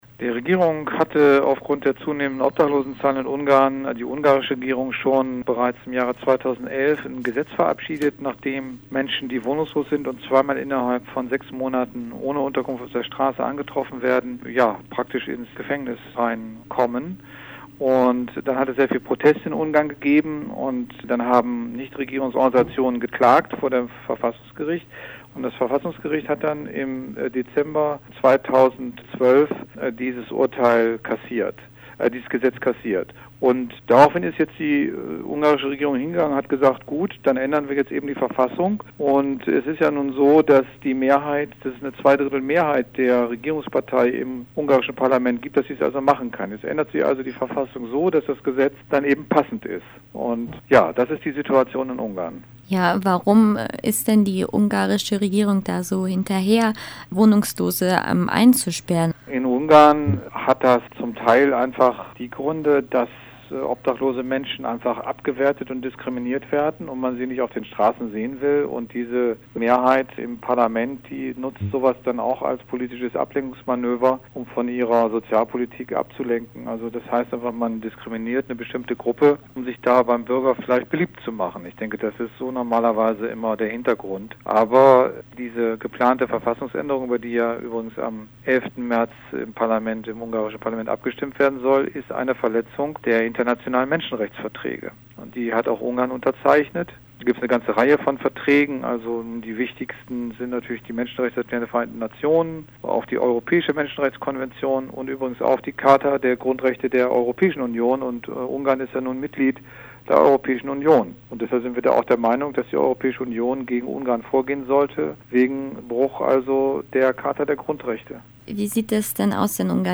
Durch eine Verfassungsänderung soll das ungarische Parlament die Bestrafung von Obdachlosigkeit ermöglichen - ja einfach so. Ein Interview